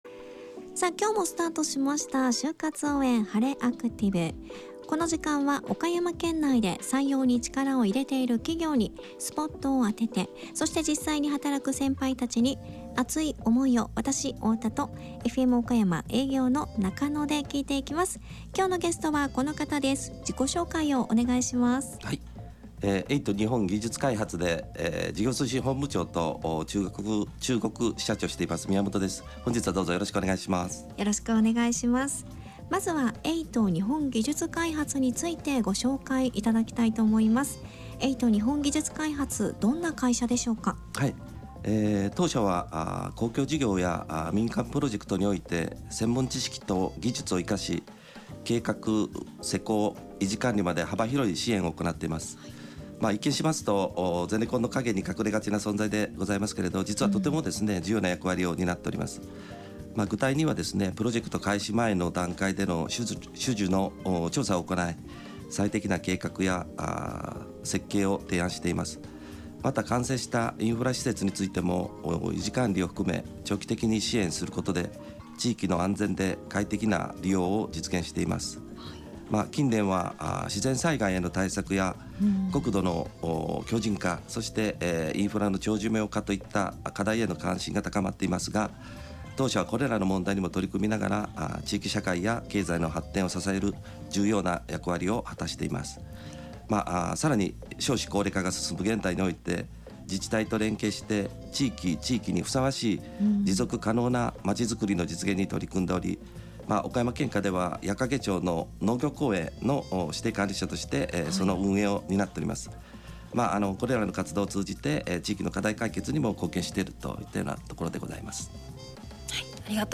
当日録音された音声データ（mp3 8.5MB）（掲載期間2026年8月9日まで） FM岡山のスタジオにて FM岡山のスタジオにて